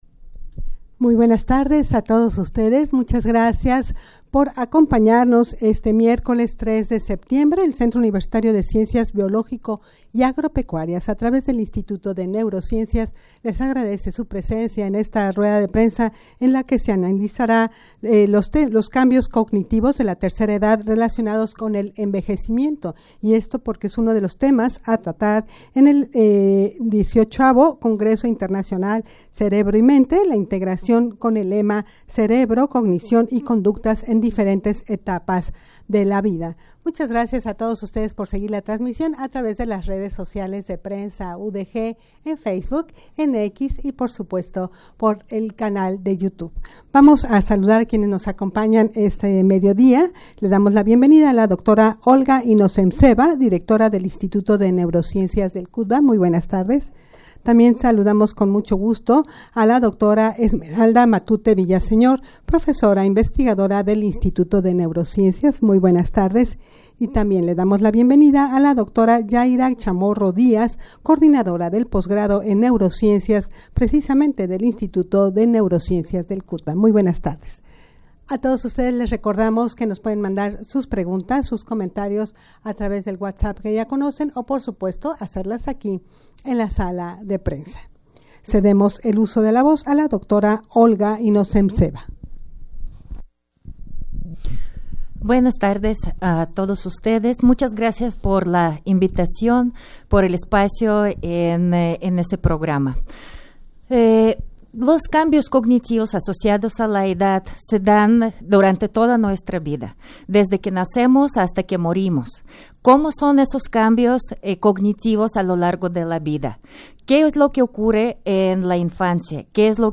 Audio de la Rueda de Prensa
rueda-de-prensa-en-la-que-se-analizara-los-cambios-cognitivos-de-la-tercera-edad-relacionados-con-el-envejecimiento.mp3